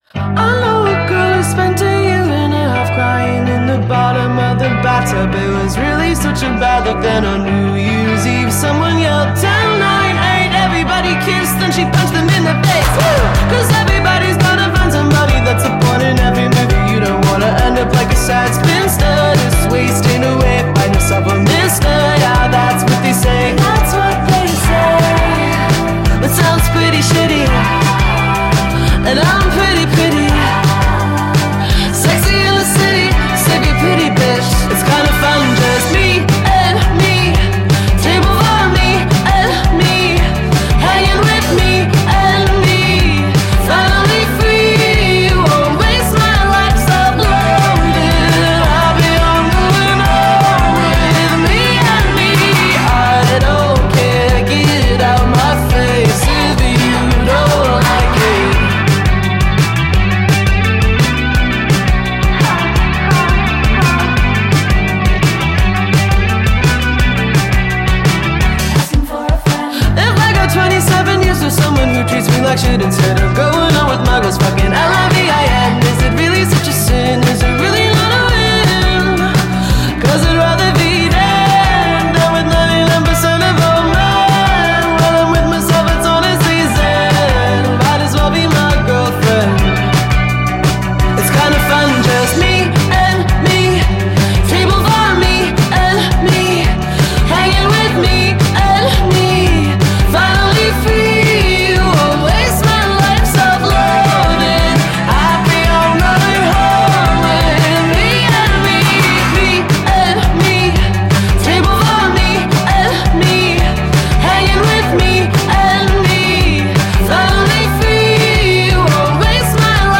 ایندی فورعور